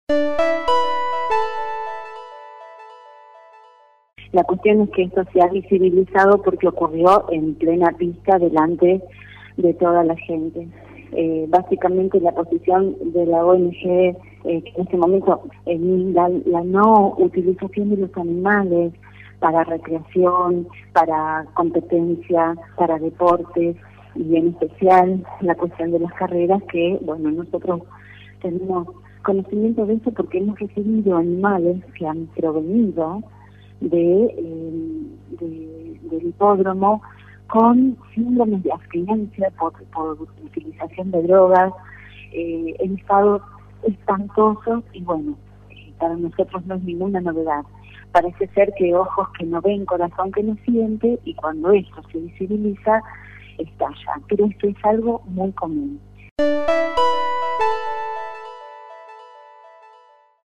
En contacto con el móvil de LT3